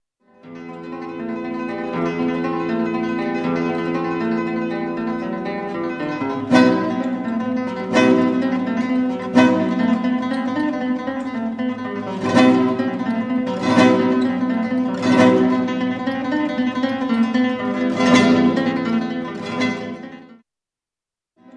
Guitarra CLÁSICA / ESPAÑOLA
Ten unha sonoridade rica e profunda, grazas a súa caixa de resonancia de madeira, ideal para tocar fermosas melodías ou ben acompañar a outros instrumentos.
guitarra.mp3